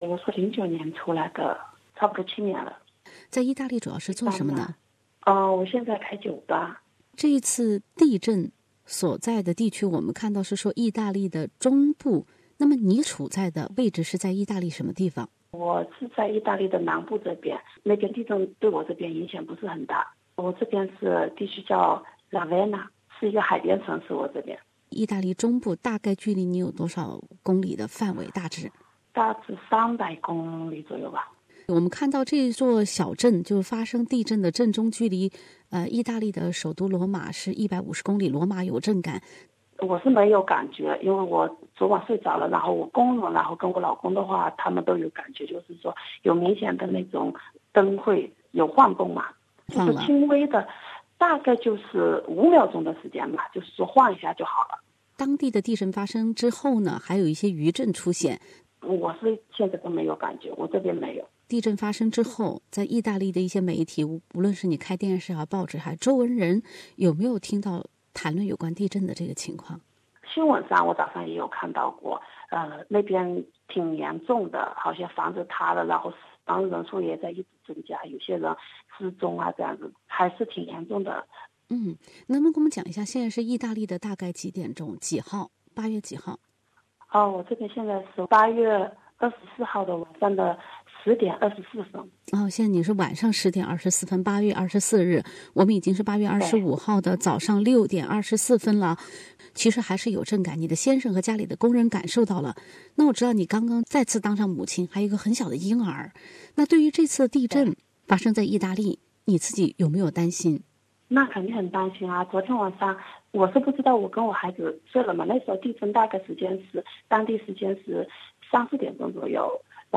ITALY EARTHQUAKE Source: BY AAP